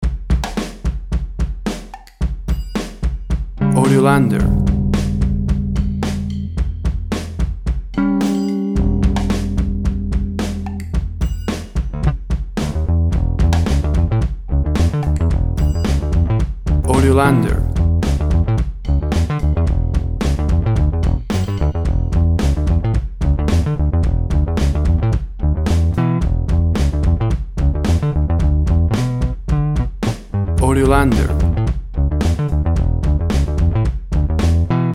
groove, bass Riff,  with the old school sounds.
WAV Sample Rate 16-Bit Stereo, 44.1 kHz
Tempo (BPM) 110